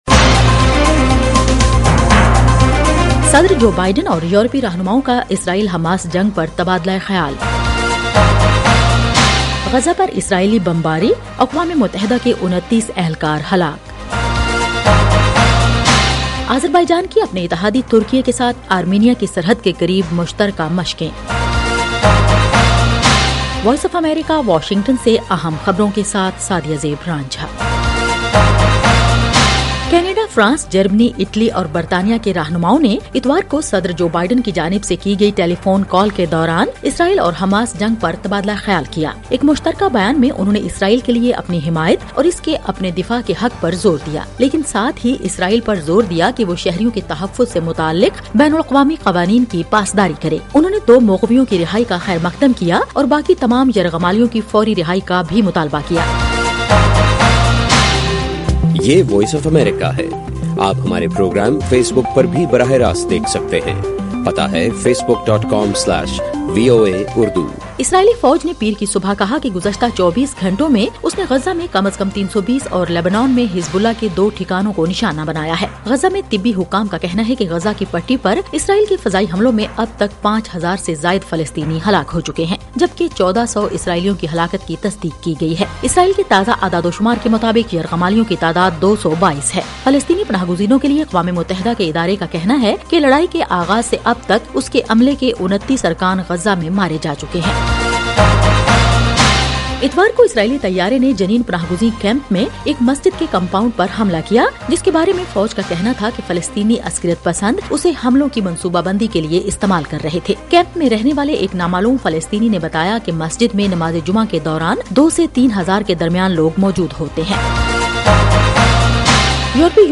ایف ایم ریڈیو نیوز بلیٹن : رات 8 بجے